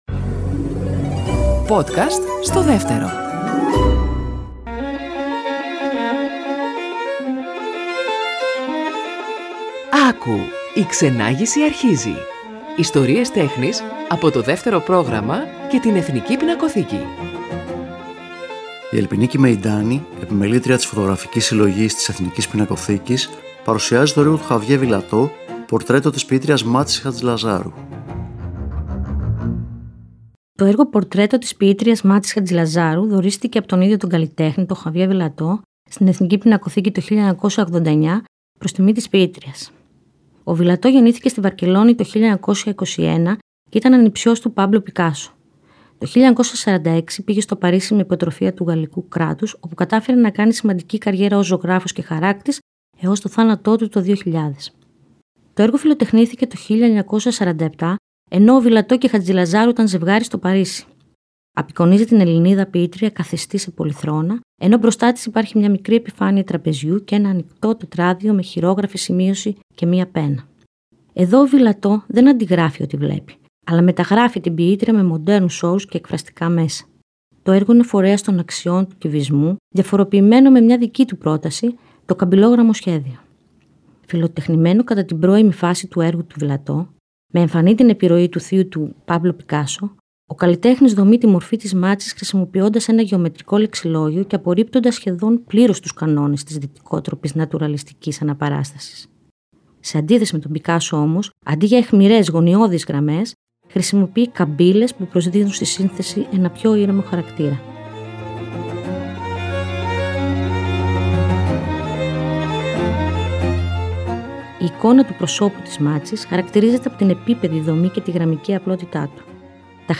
Κείμενο / Αφήγηση